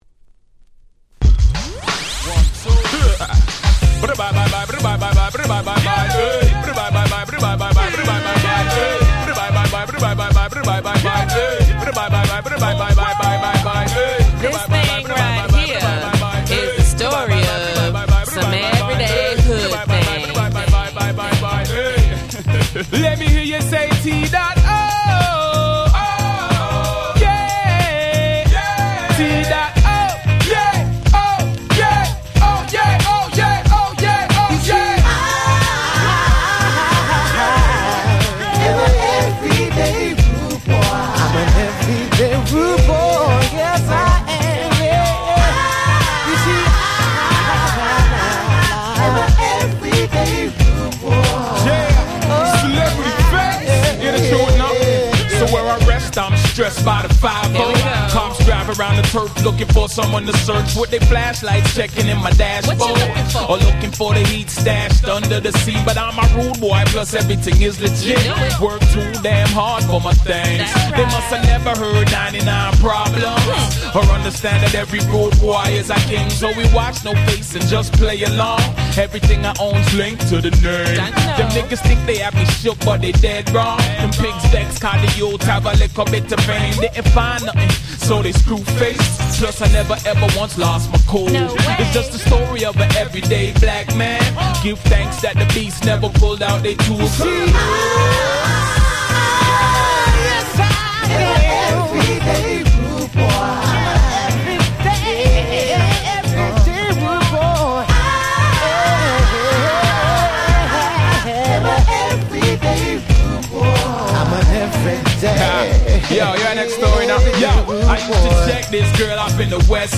05' Nice Hip Hop EP !!
キャッチー系 ラガ